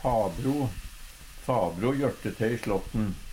DIALEKTORD PÅ NORMERT NORSK fabro far sin bror - onkel Eintal ubunde Eintal bunde Fleirtal ubunde Fleirtal bunde Eksempel på bruk Fabro jørtte te i slåtten.